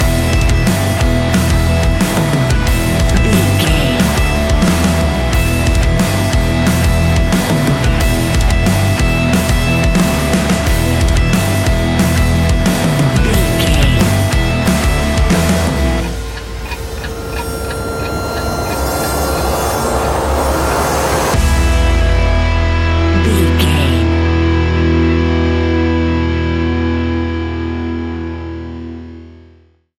Ionian/Major
E♭
hard rock
guitars
instrumentals